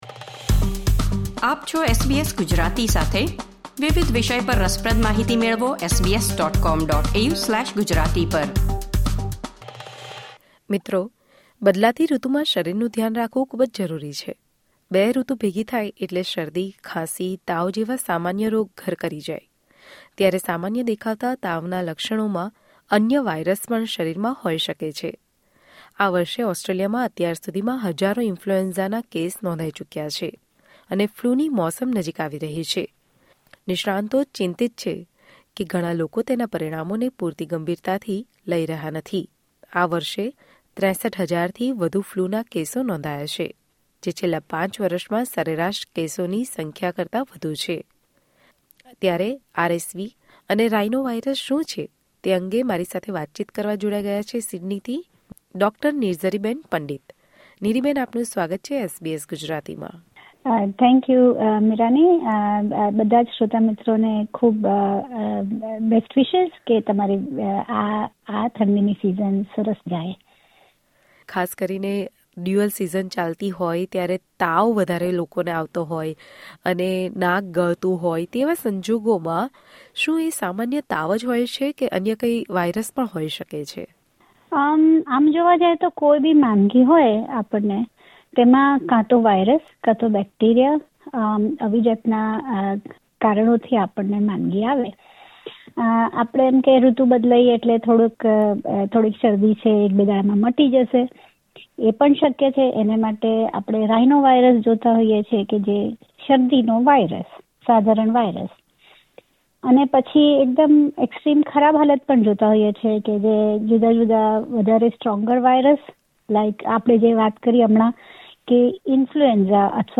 Then, in addition to the symptoms of normal fever, other viruses may also be present in the body. Sydney based Dr. is talking about this.